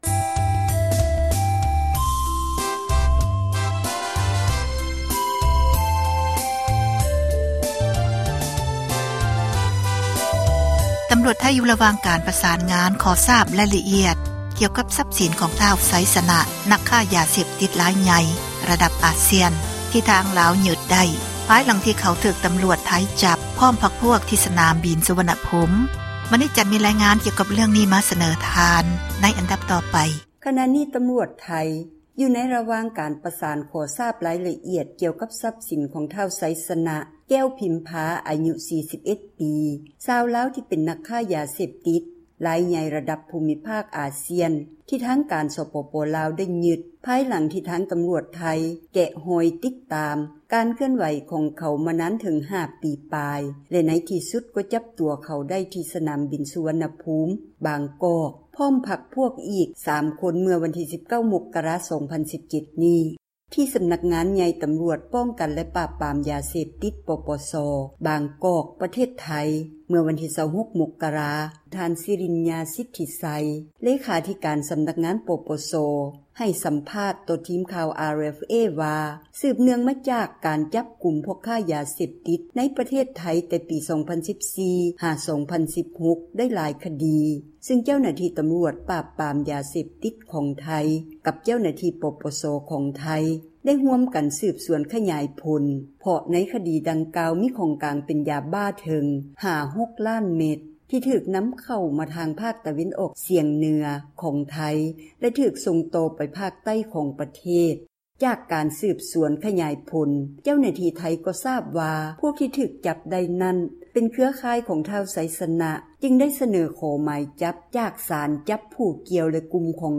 ຂ່າວລາວ ວິທຍຸເອເຊັຽເສຣີ ພາສາລາວ